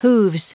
Prononciation du mot hooves en anglais (fichier audio)
Prononciation du mot : hooves